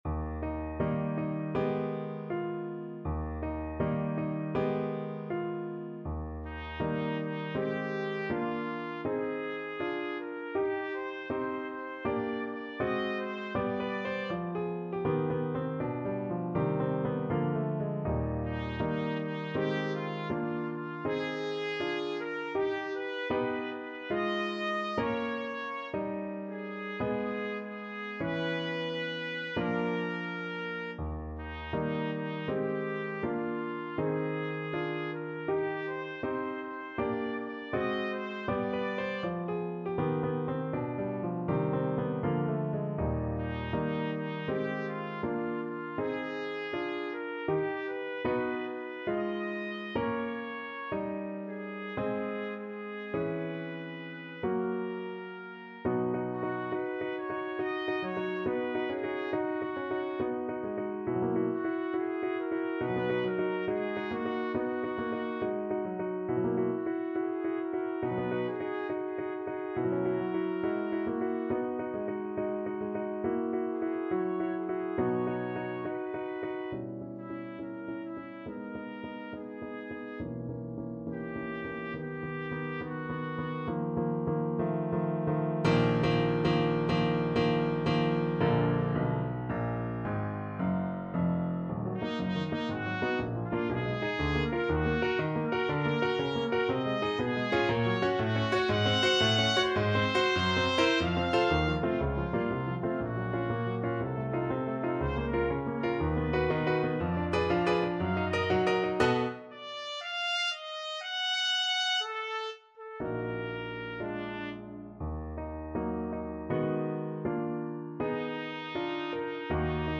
2/4 (View more 2/4 Music)
Moderato =80
Classical (View more Classical Trumpet Music)